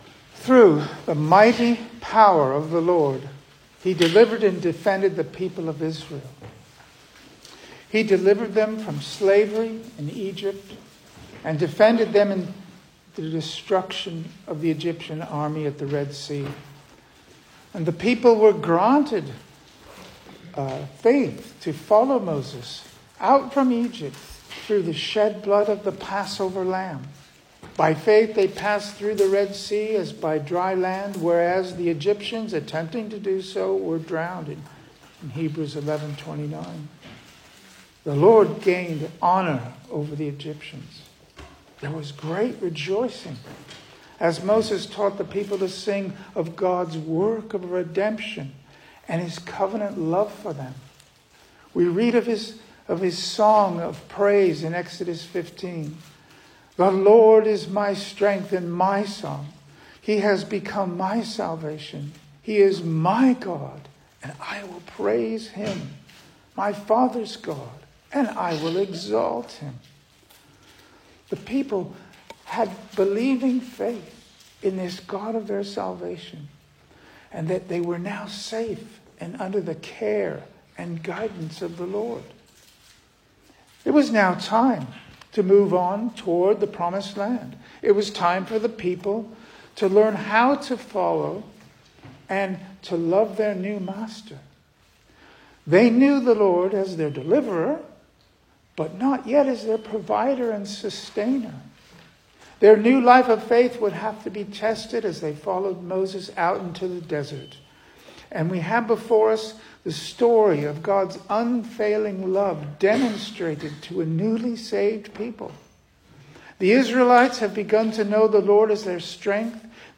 2025 Service Type: Sunday Morning Speaker
Single Sermons Book